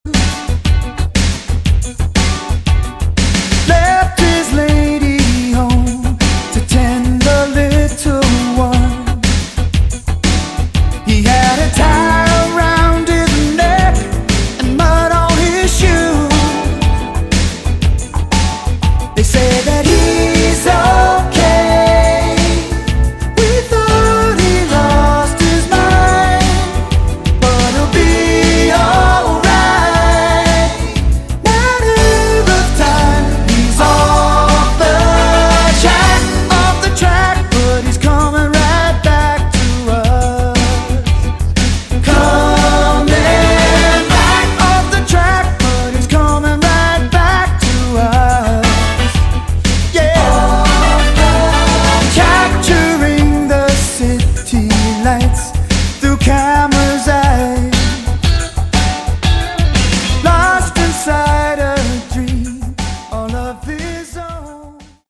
Category: Westcoast AOR